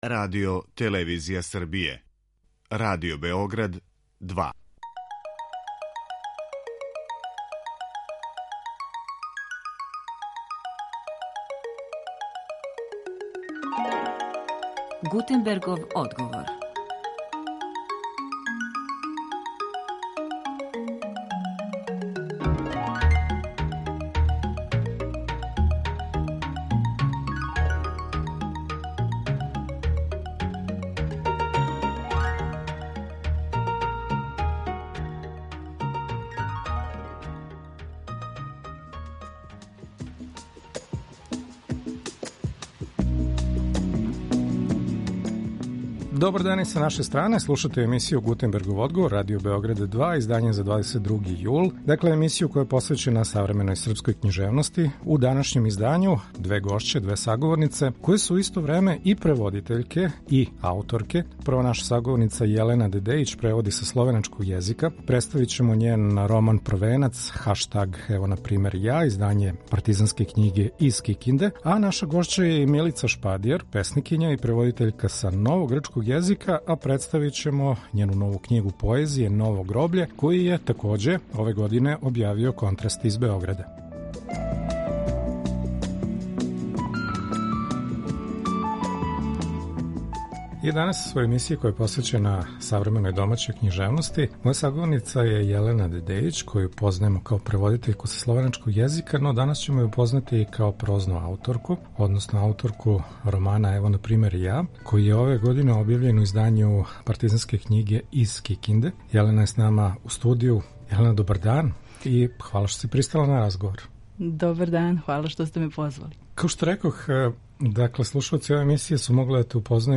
У данашњој емисији гошће су две преводитељке које пишу, две ауторке из Београда на почетку свог опуса.